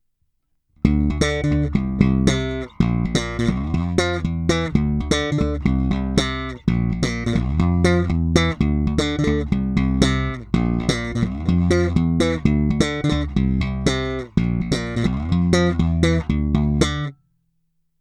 Následující nahrávky jsou provedeny rovnou do zvukové karty a dále ponechány bez jakýchkoli úprav, kromě normalizace samozřejmě.
Slap